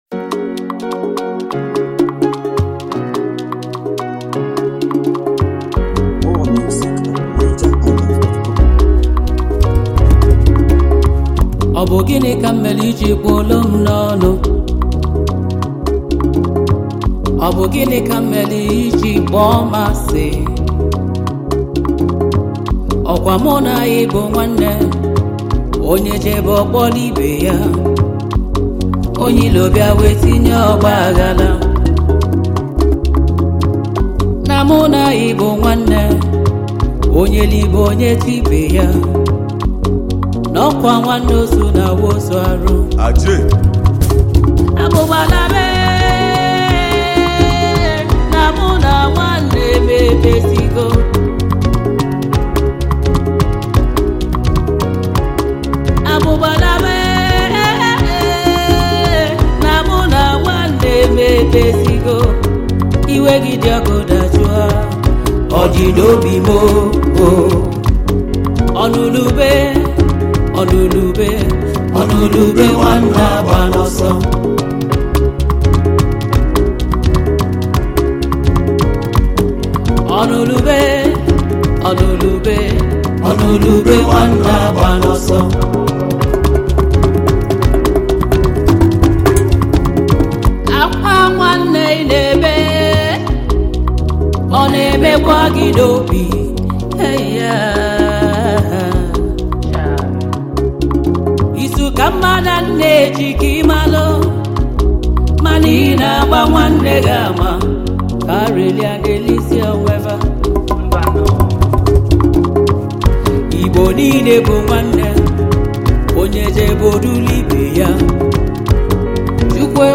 Home » Gospel
Igbo gospel song